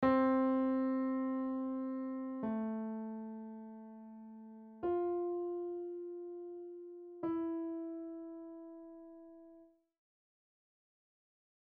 Look at the letters and find the notes on your piano; play the words
Piano Notes